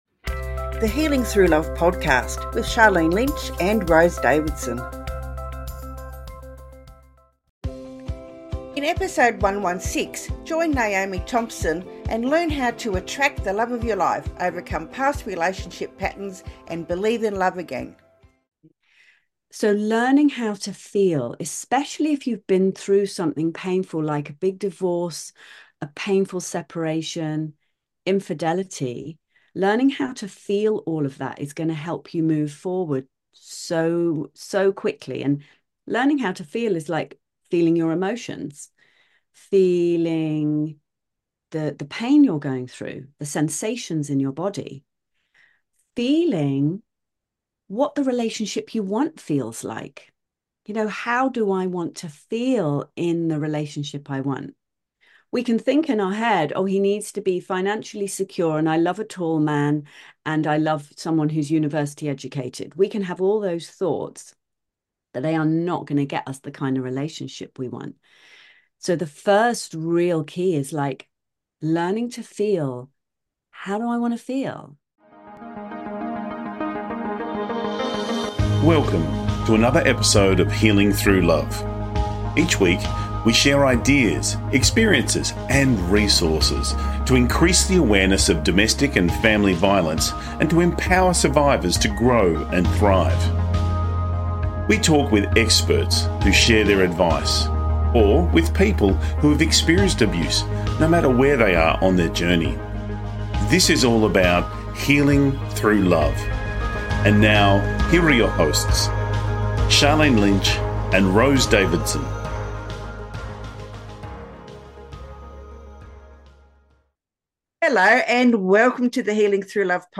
Key Points from the Interview: The unconscious habits that may be pushing love away and how to break free from them. The power of self-love and its role in attracting fulfilling, healthy relationships.